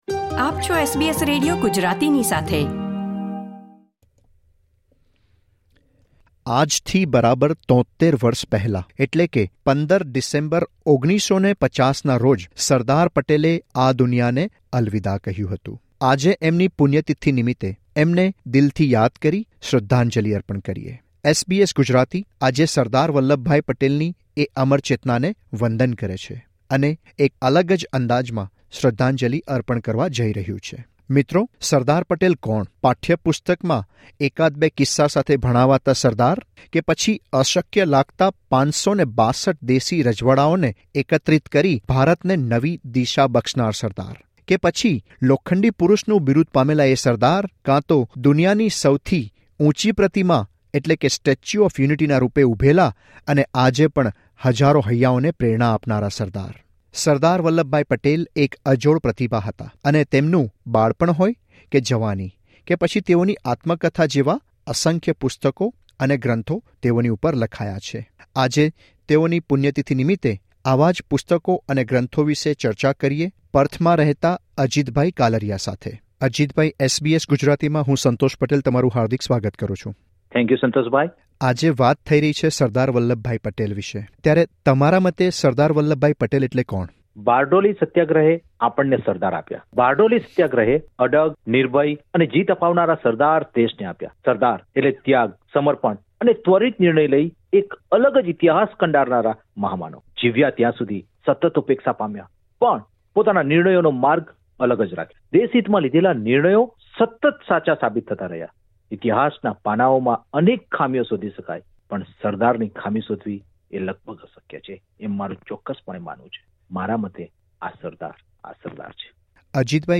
માહિતી આપી રહ્યા છે બ્લોગર અને ભૂતપૂર્વ શિક્ષક